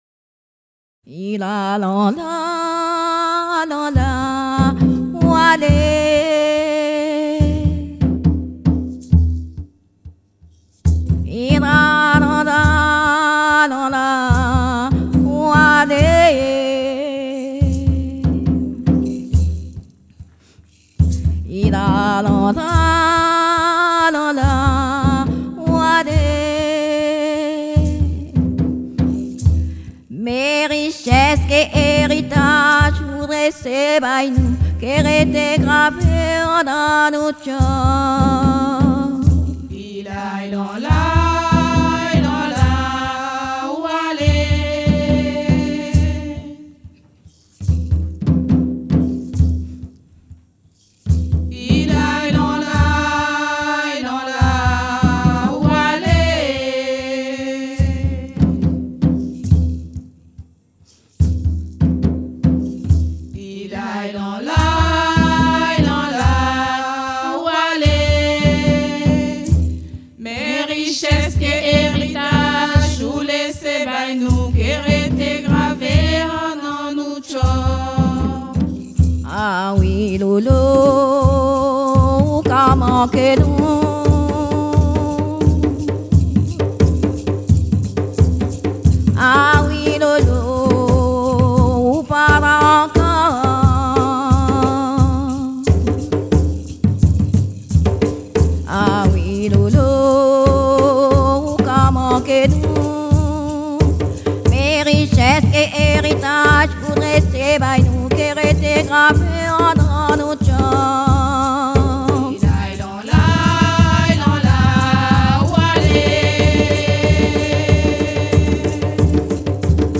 danse : léròl (créole)
Pièce musicale inédite